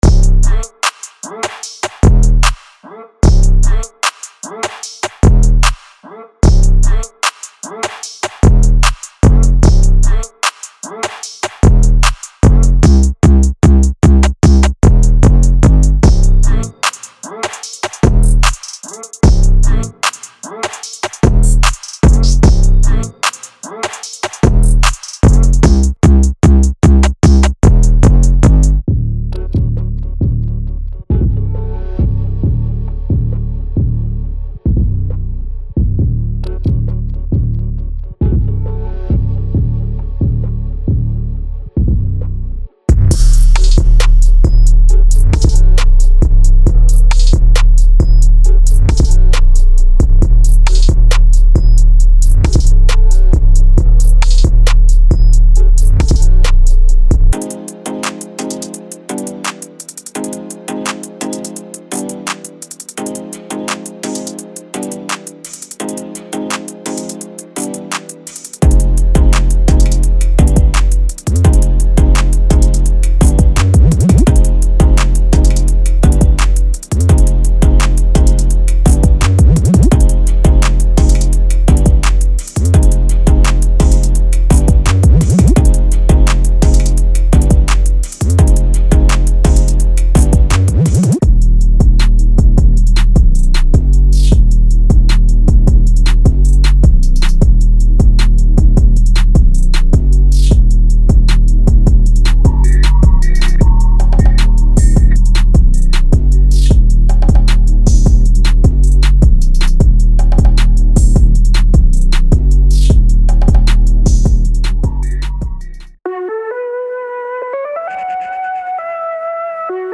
最先端の808、ドラム、パーカッション、ベースライン、メロディ、FXサウンドにご期待ください。
デモサウンドはコチラ↓
Genre:Trap